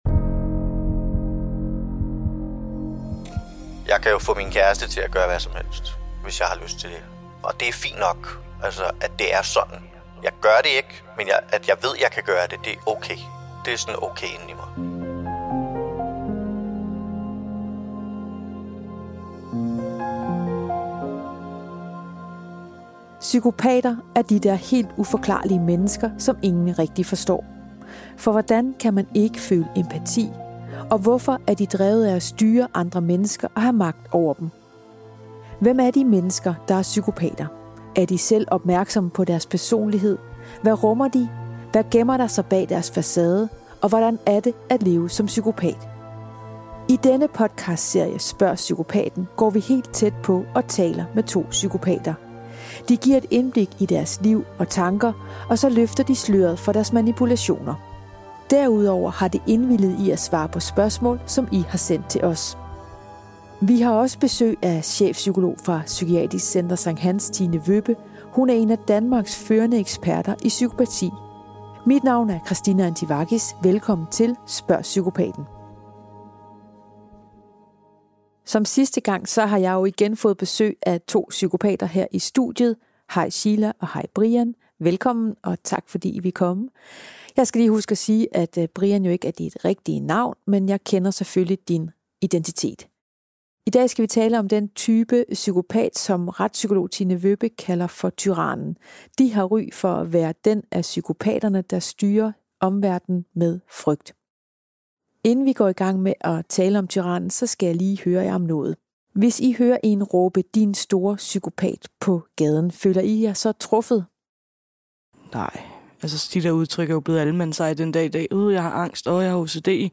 Mød to psykopater, der selv har udøvet den form for magt og som svarer på spørgsmål om deres liv og tanker som dyssocial.